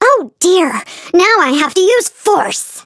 flea_hurt_vo_03.ogg